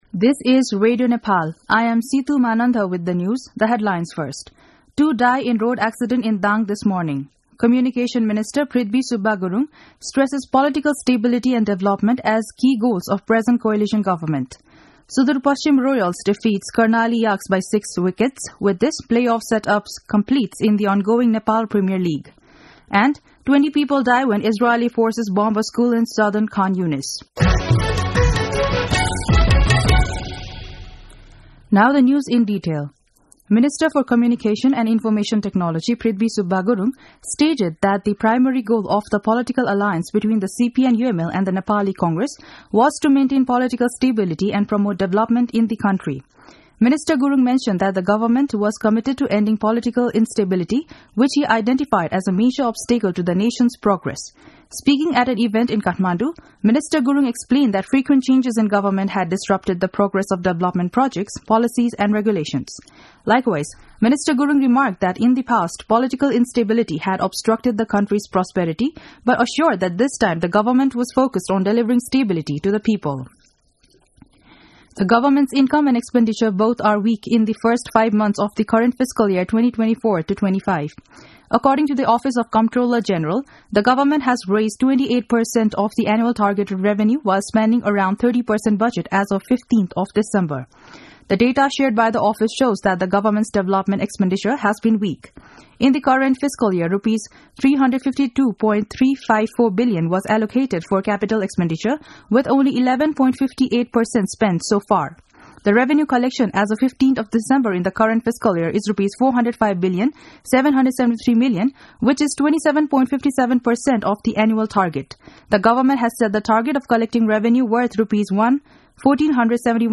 दिउँसो २ बजेको अङ्ग्रेजी समाचार : २ पुष , २०८१
2-pm-enlish-news-.mp3